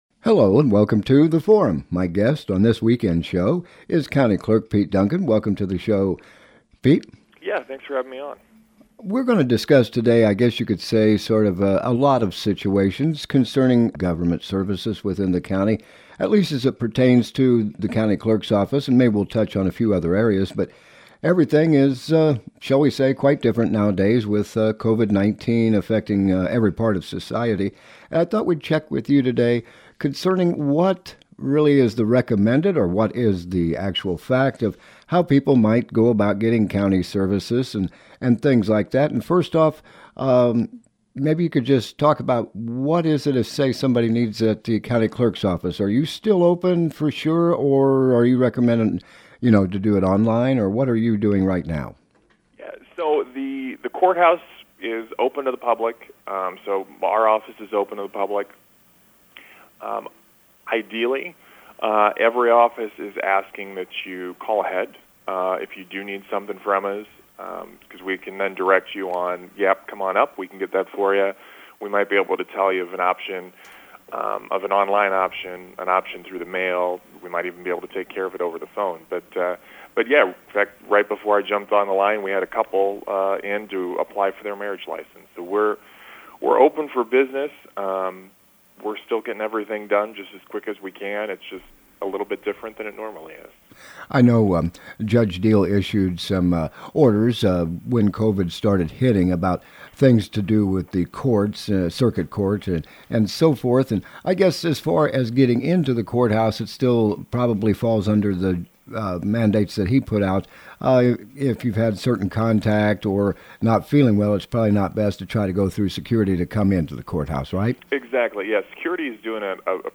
Topic: County Government Services During Covid-19 Guest: Pete Duncan - Macoupin County Clerk